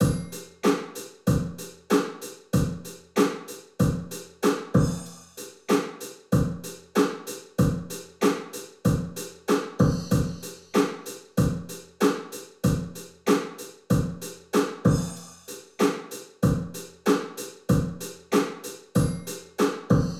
I also wanted to try capturing the bathroom downstairs, it’s about 2m x 2m square, completely tiled floor and walls with a textured ceiling, and is quite reverberant.
With 100% wet  ‘Bathroom 1’ IR:
This one has a splashy, hard character.
ir-example-drum-loop-100-wet-bathroom-1.wav